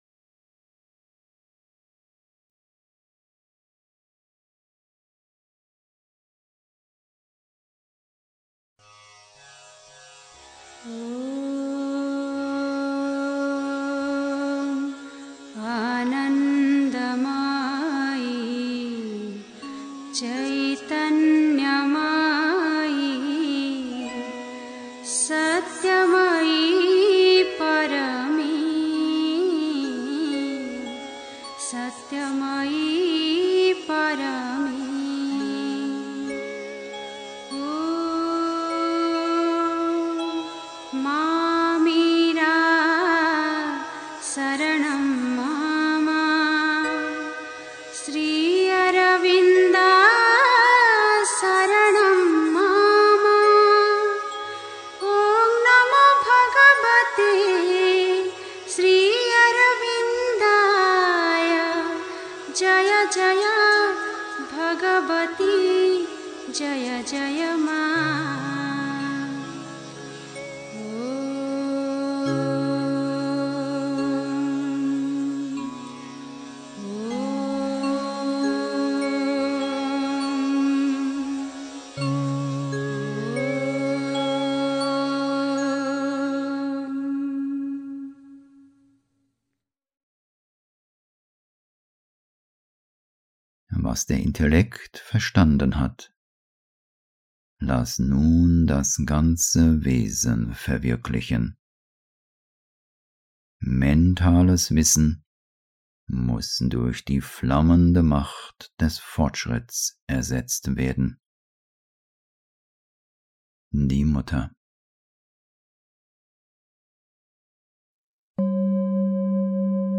1. Einstimmung mit Musik. 2. Mentales Wissen muss durch die flammende Macht des Fortschritts ersetzt werden (Die Mutter, CWM, Vol. 12, p. 141) 3. Zwölf Minuten Stille.